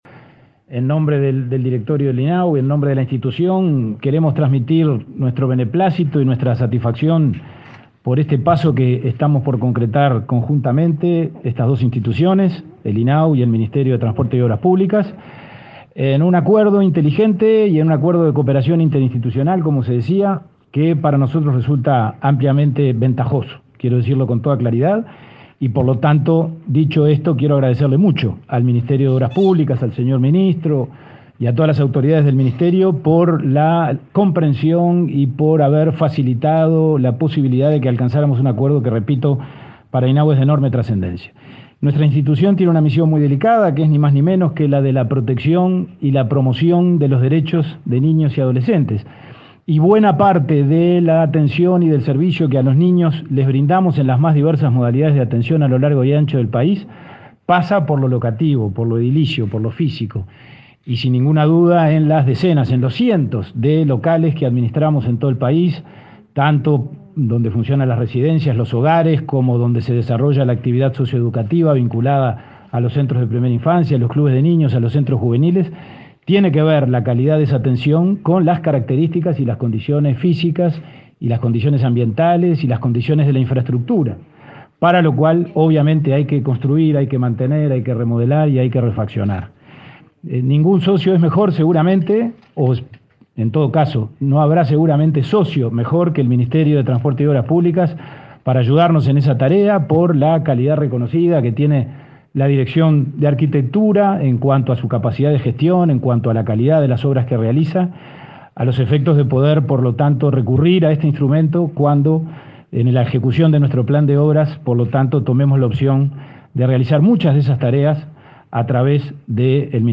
Palabras del presidente de INAU y del ministro de Transporte